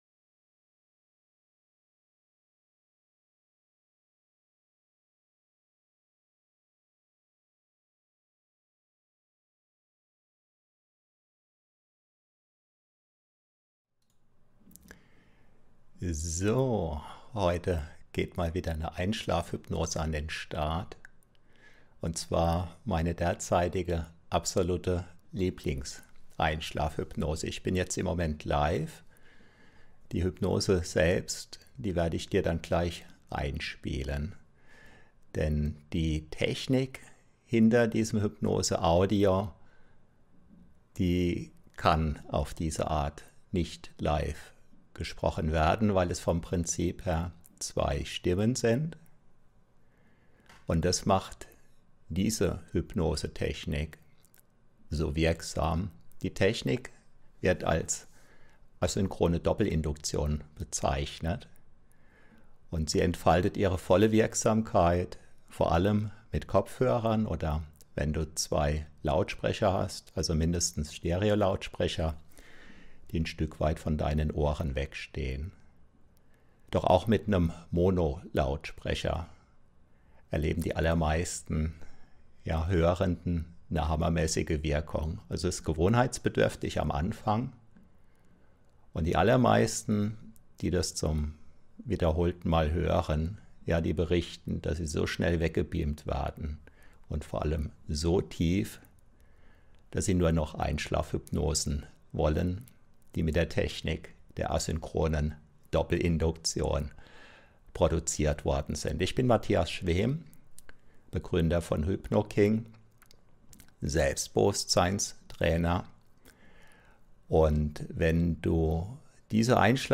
Hypnose Einschlafen ACHTUNGe extrem stark: Asynchrone Doppelinduktion! Abschied, nicht mehr Opfer ~ HypnoKing® Hypnosen zum Einschlafen, Durchschlafen, Tiefschlafen gratis aufs Handy, Tablet & Co Podcast